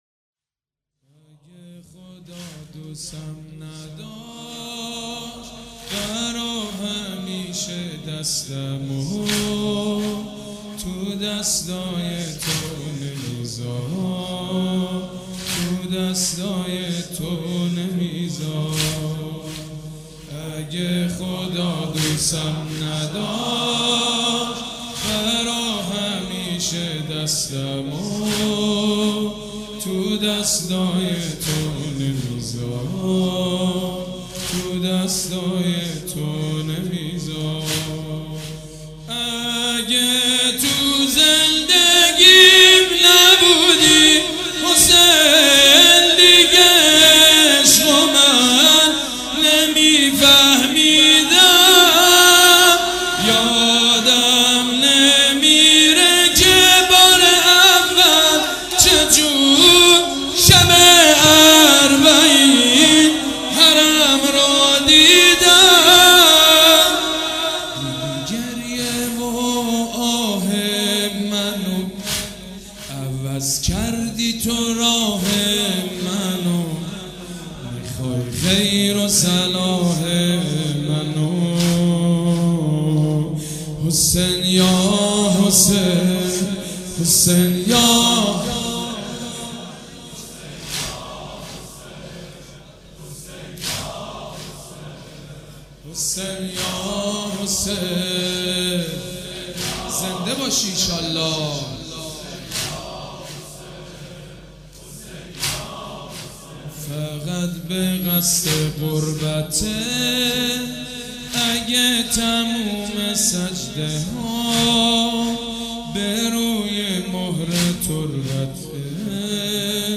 صوت مراسم شب چهارم محرم 1438هیئت ریحانة الحسین(ع) ذیلاً می‌آید:
بخش نهم-واحد-روز عاشورا که خورشید فروزنده عیان گشت و منور